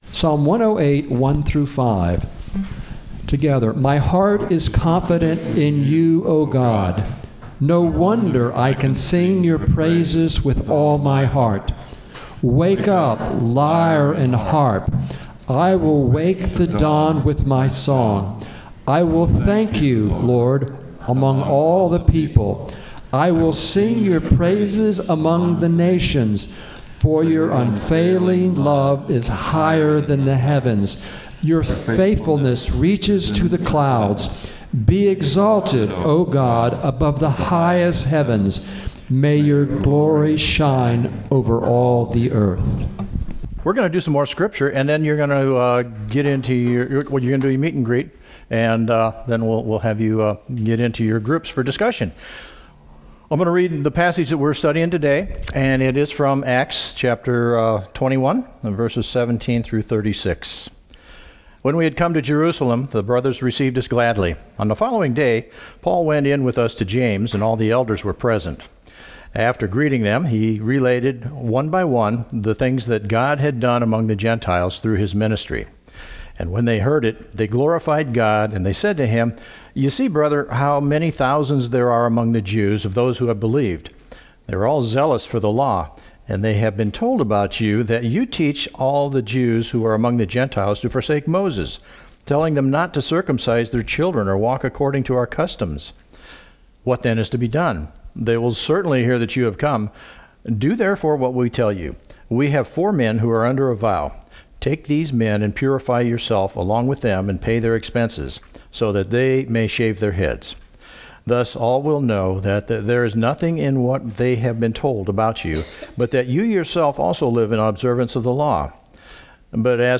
Acts 21:17-36 Service Type: Gathering The Apostle Paul had been praying to be able to go to Rome for a long time.